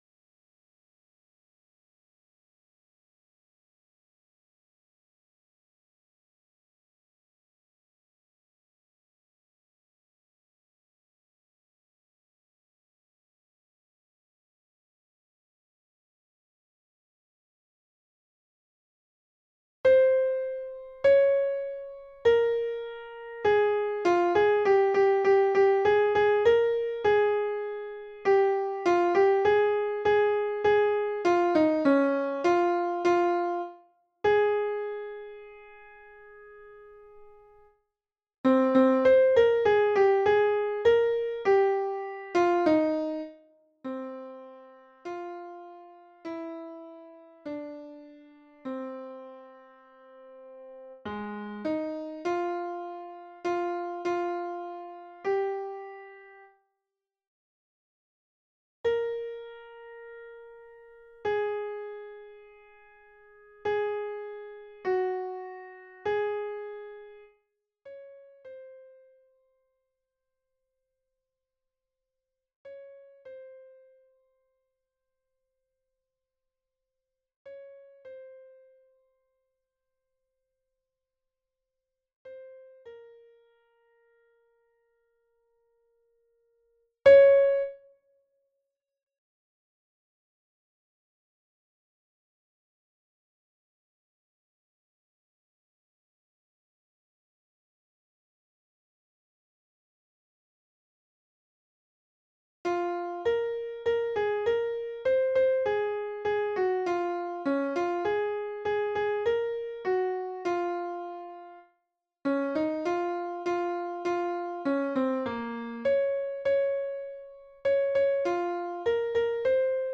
Soprano (version piano)